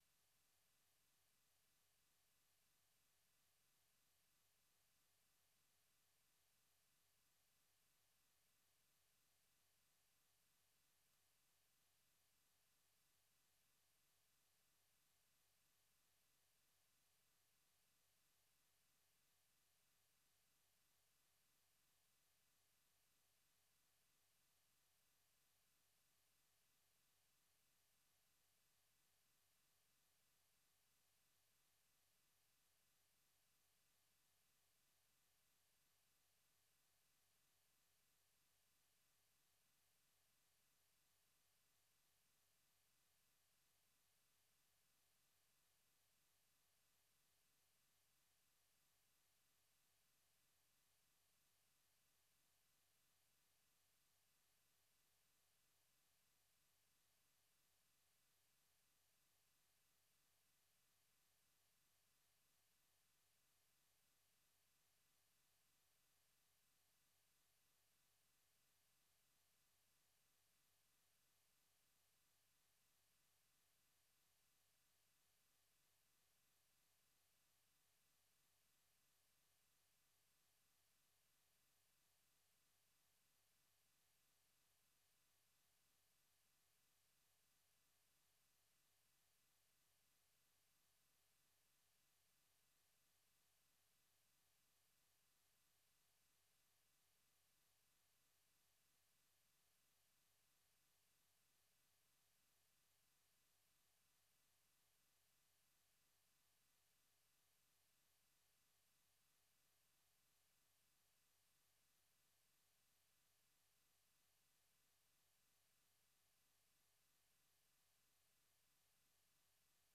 Silohlelo lwezindaba esilethulela lona kusukela ngoMvulo kusiyafika ngoLwesine emsakazweni weStudio 7 ngo6:30am kusiyafika ihola lesikhombisa - 7:00am.